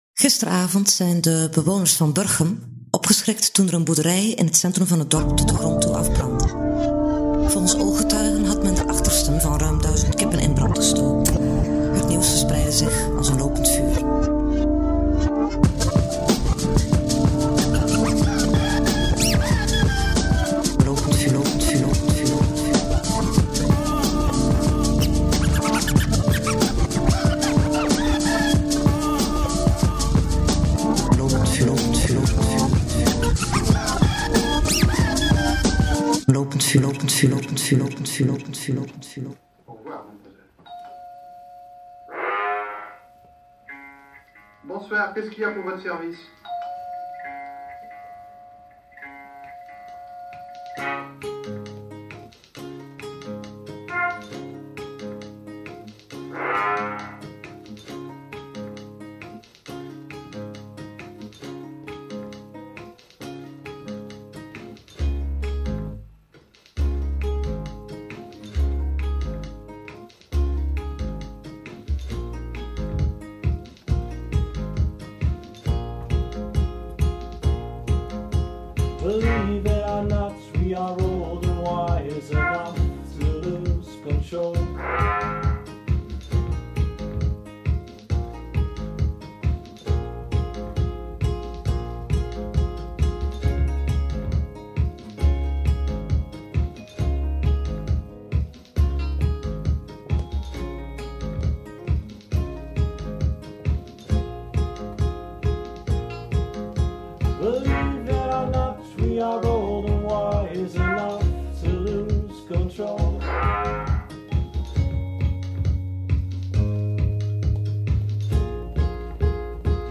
Daags na de allereerste editie van Lopend Vuur Offline presenteren we u de eerste Lopend Vuur podcast die buiten de deur is opgenomen.
Ditmaal was Lopend Vuur dus te gast, en wel in Leeuwarden bij Jean Parlette. Deze folktronic band, ook wel bekend als misschien de rijkste band van Friesland, speelde drie nummers live en gaf antwoord op onze vragen.